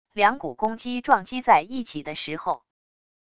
两股攻击撞击在一起的时候wav音频